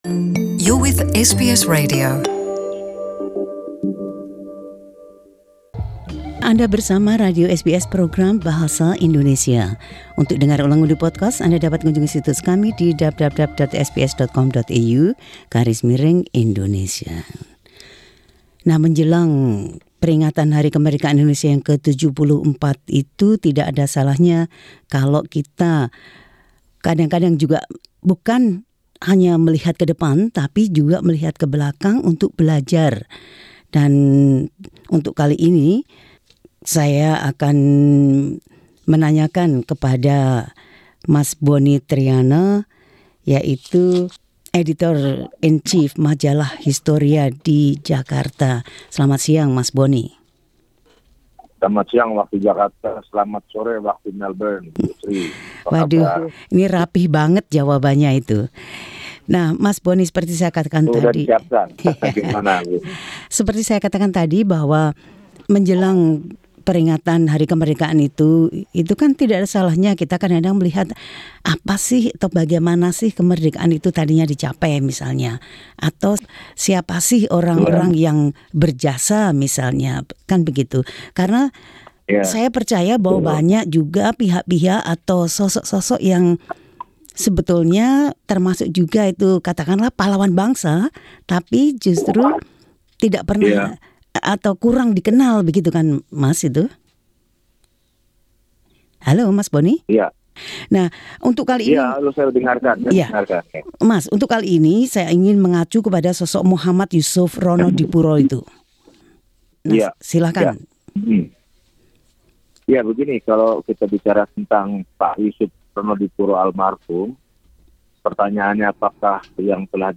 Pemimpin Redaksi Majalah Historia, Bonnie Triyana berbicara tentang seorang jurnalis muda yang walaupun dalam pendudukan Jepang berhasil menyiarkan proklamasi kemerdekaan Indonesia kepada dunia.